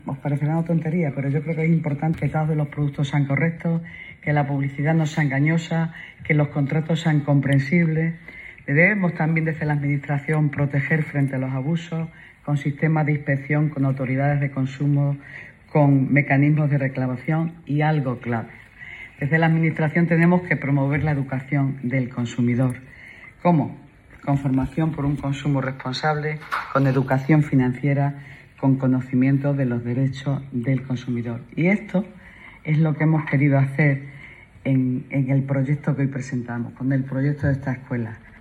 La delegada de Economía, Innovación y Hacienda, Engracia Hidalgo:
AUDIO-ENGRACIA.-Escuela-Municipal-de-Consumo.mp3